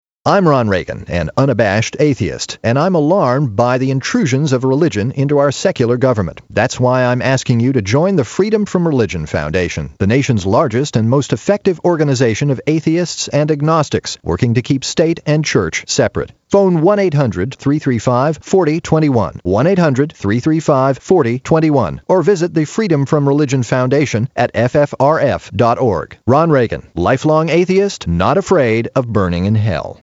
President Ronald Reagan’s Son Tapes Radio Ad for Atheist Group: I’m ‘Not Afraid of Burning in Hell’